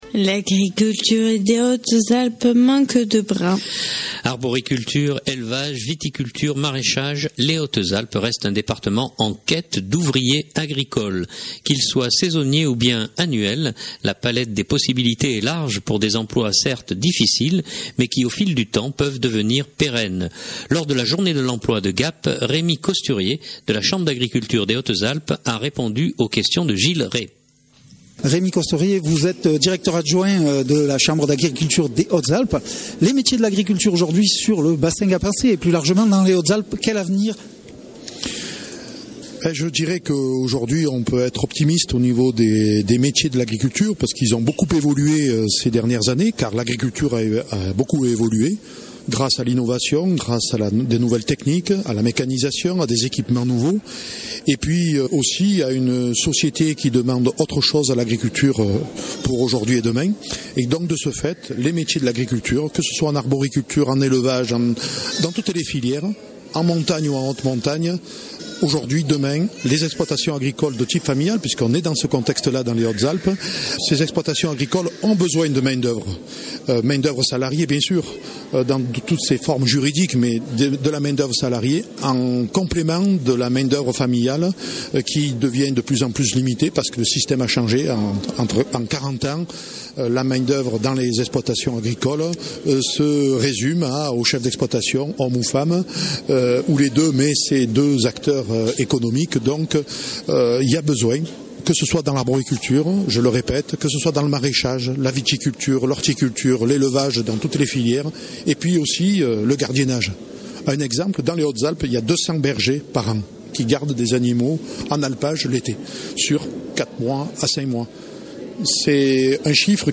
Lors de la journée de l’emploi de Gap